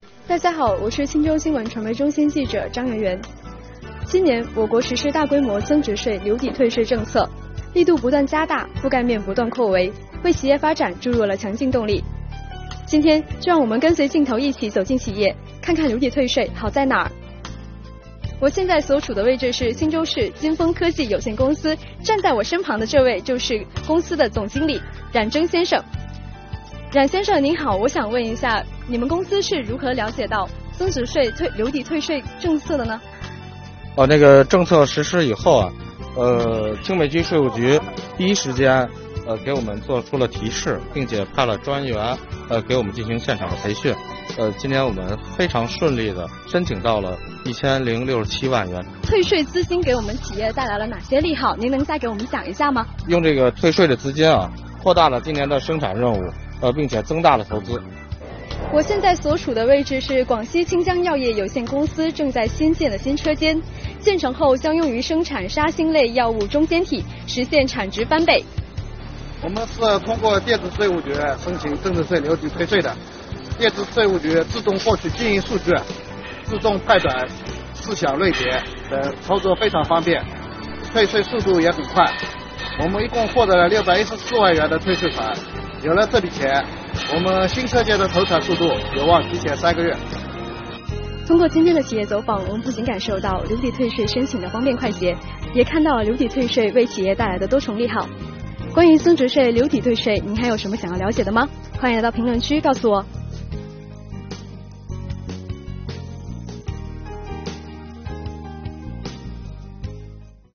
让我们跟随记者的镜头，一起探访留抵退税实施效果……